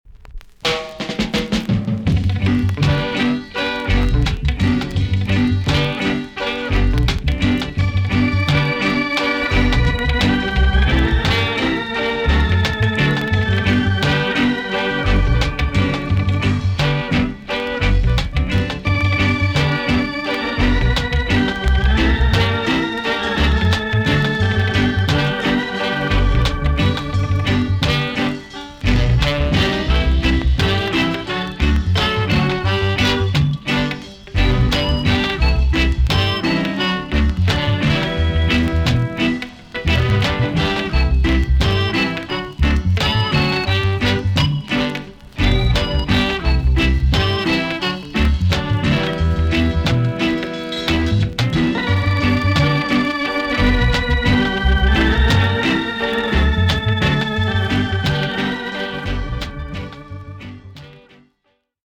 TOP >SKA & ROCKSTEADY
VG+~VG ok 軽いチリノイズが入ります。
INST